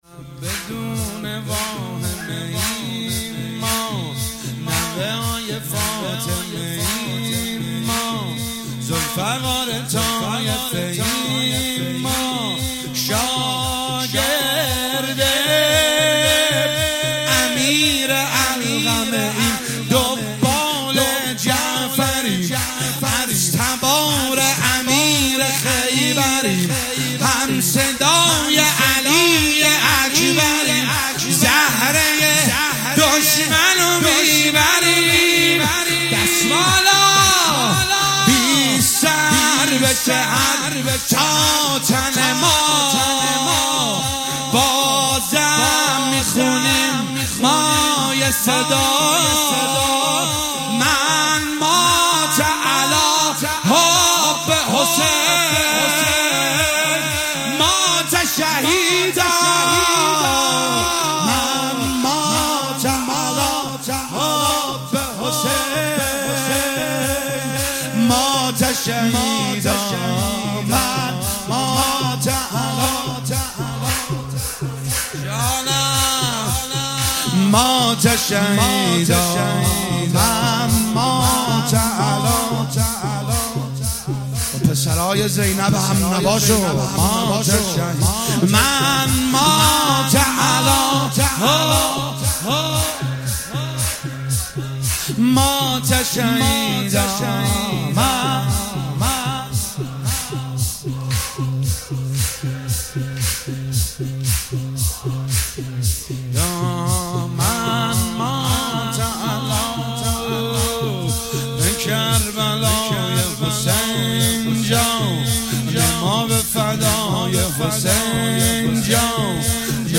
زمینه- بدون واهمه ایم ما
محرم1401 شب چهارم